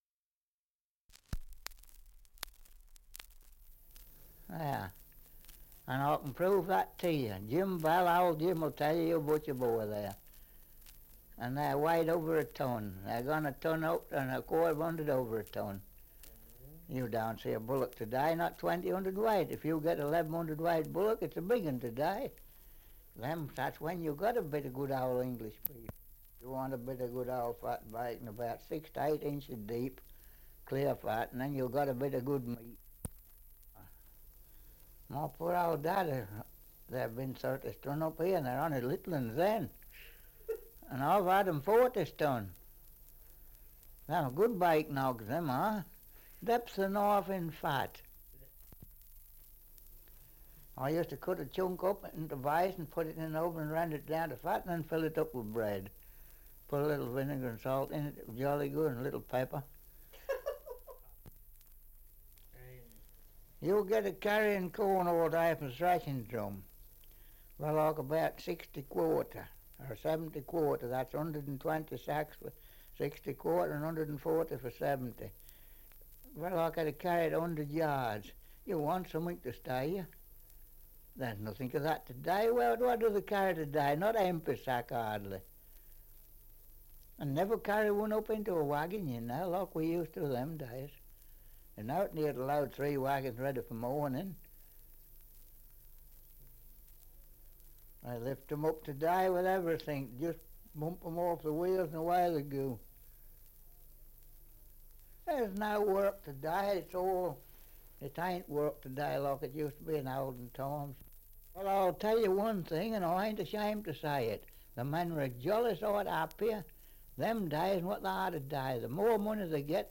Survey of English Dialects recording in Warmington, Northamptonshire
78 r.p.m., cellulose nitrate on aluminium